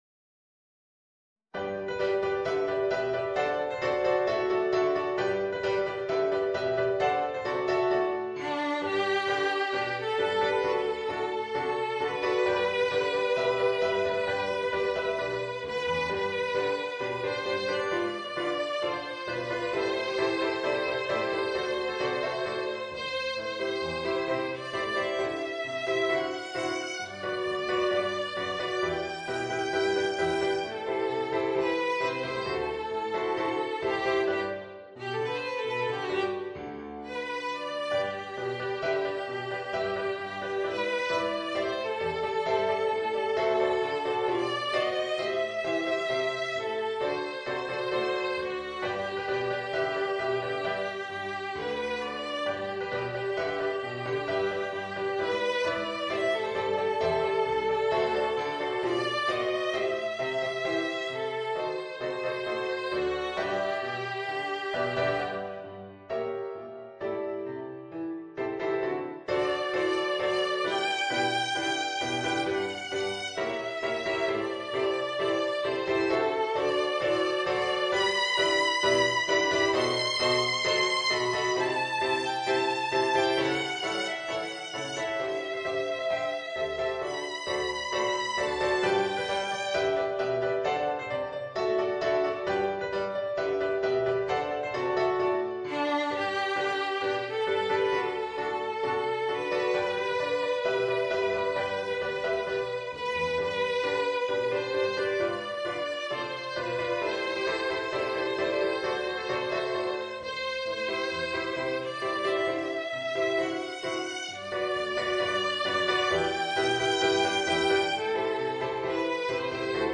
Voicing: Violin and Piano